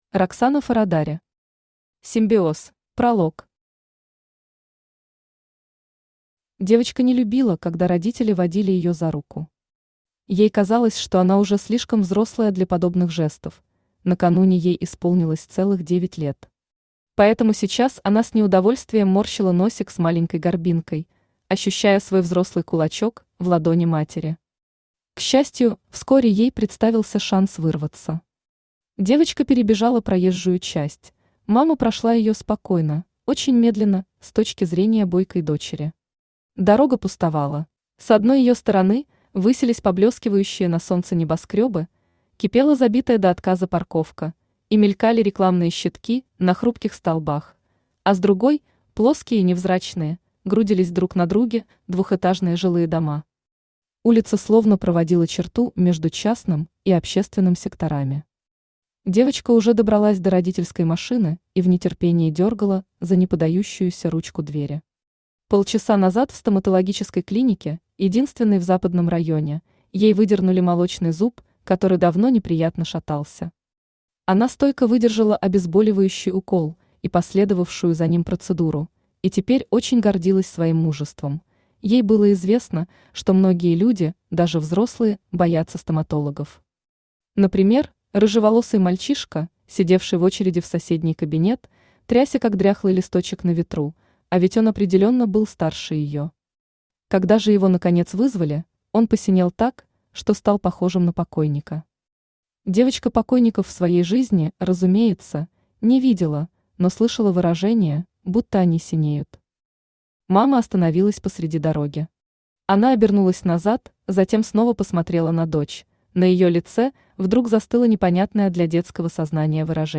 Аудиокнига Симбиоз | Библиотека аудиокниг
Aудиокнига Симбиоз Автор Роксана Форрадаре Читает аудиокнигу Авточтец ЛитРес.